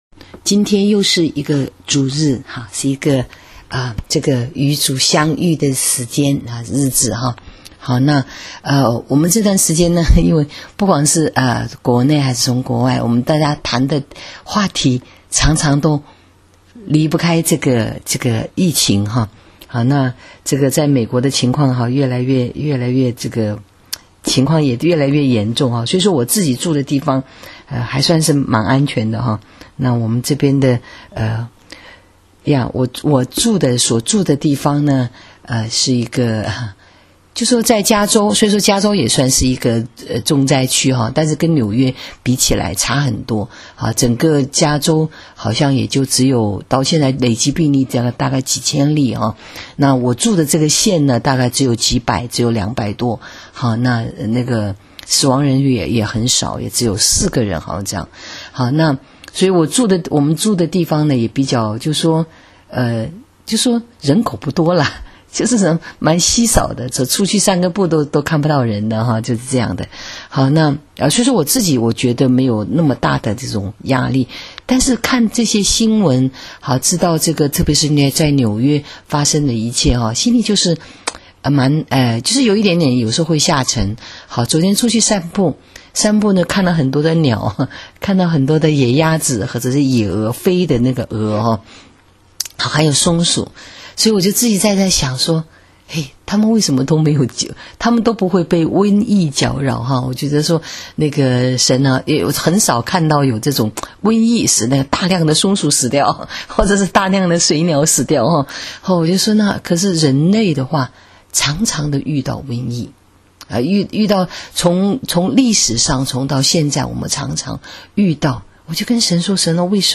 【主日信息】瘟疫中的救恩 3-29-20